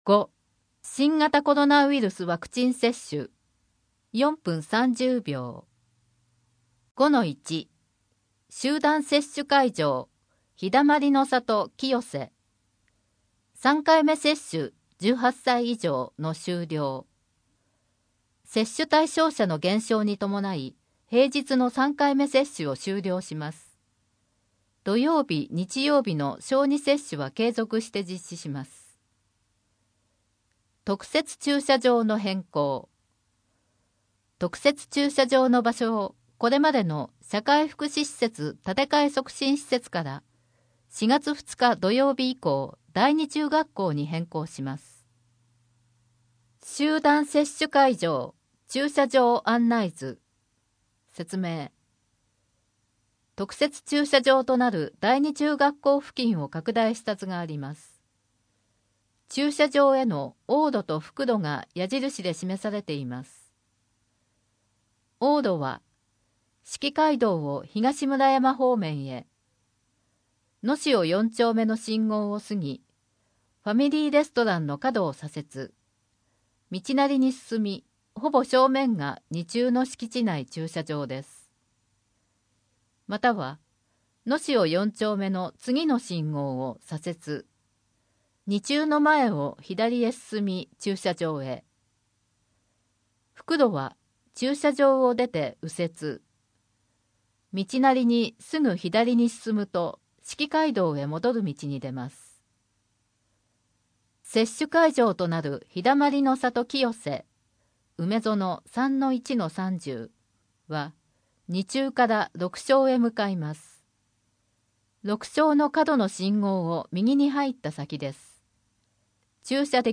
郷土博物館のイベント 自然観察会 4・5月の子育て関連事業 4月の休日診療 24時間電話で聞ける医療機関案内 平日小児準夜間診療 令和4年4月1日号8面 （PDF 673.4 KB） 声の広報 声の広報は清瀬市公共刊行物音訳機関が制作しています。